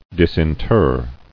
[dis·in·ter]